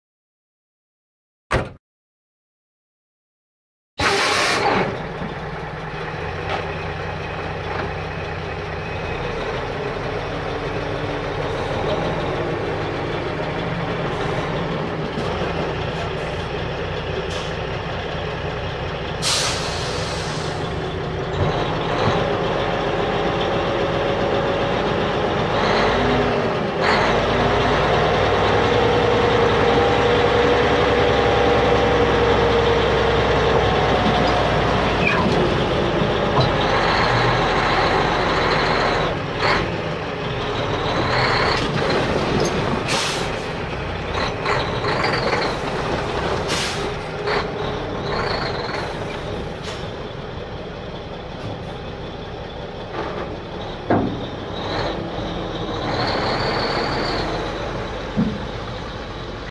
دانلود صدای باد ترمز کامیون – تریلی 3 از ساعد نیوز با لینک مستقیم و کیفیت بالا
جلوه های صوتی